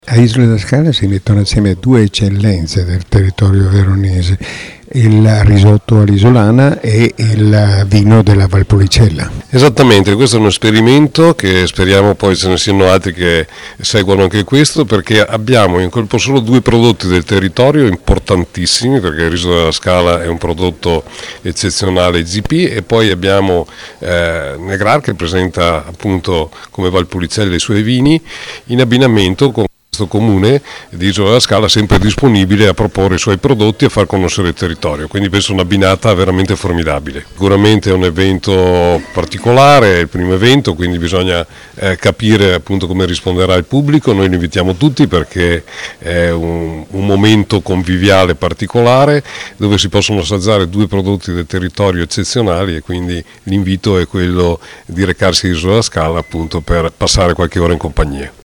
Al microfono del nostro corrispondente
il Presidente della Provincia, Flavio Pasini